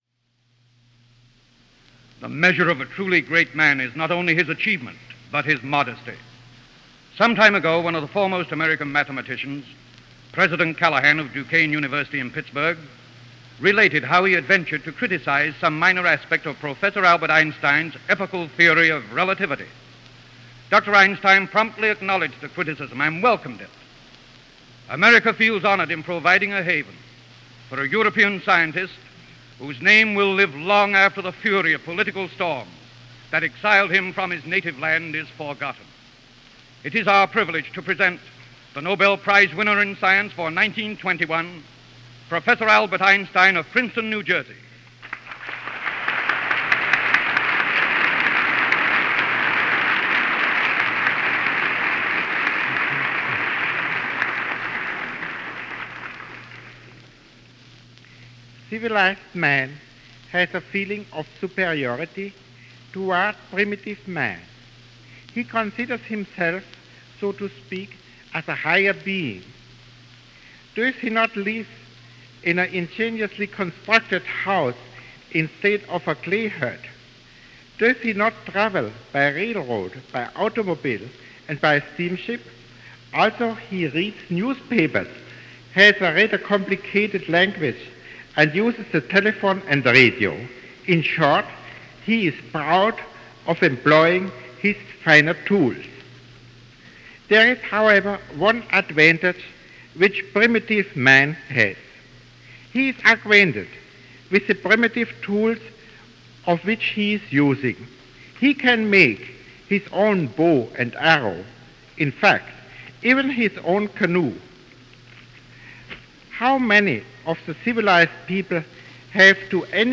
This address, given on the occasion of the opening of the Museum of Science and Industry in New York was a gala affair, and Albert Einstein was one of several speakers to dedicate the new facility.
Now you get to hear his voice, in case you’ve never heard it before.
Albert-Einstein-Opening-of-Science-Museum.mp3